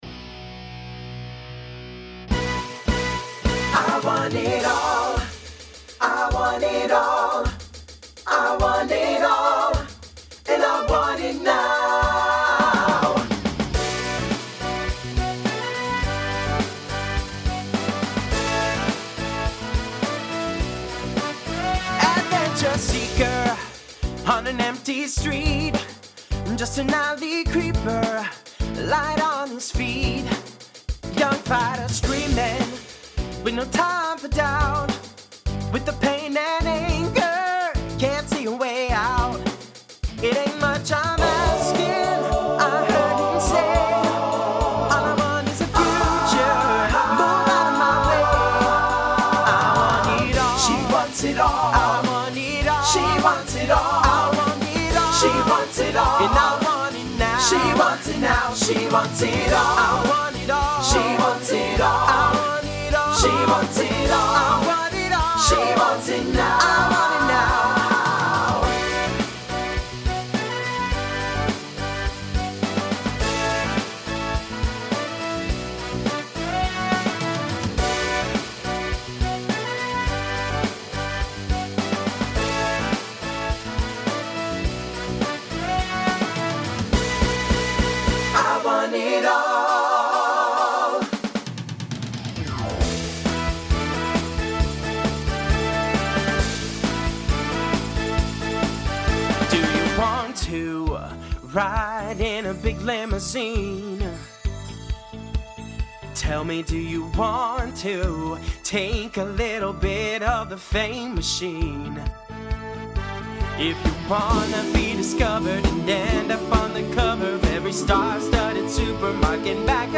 New SATB voicing for 2024.